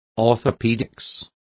Also find out how ortopedia is pronounced correctly.